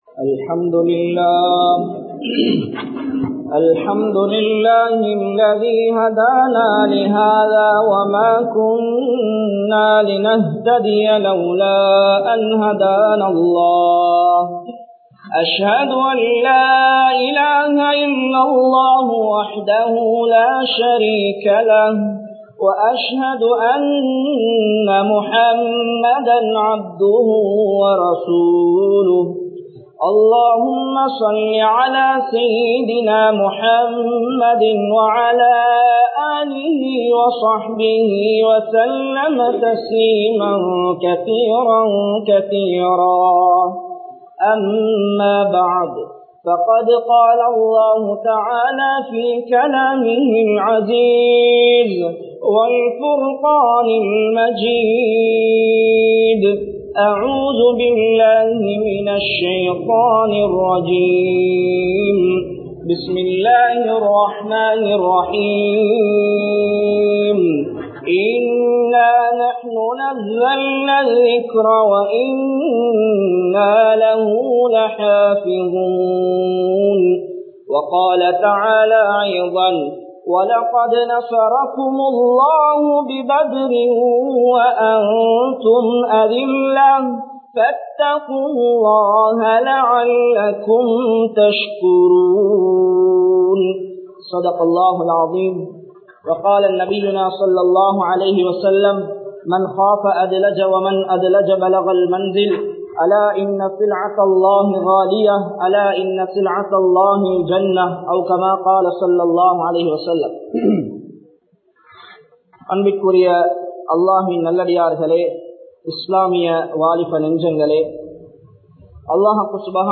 Badhu Puhattum Paadam Enna? (பத்ர் புகட்டும் பாடம் என்ன?) | Audio Bayans | All Ceylon Muslim Youth Community | Addalaichenai
Mallawapitiya Jumua Masjidh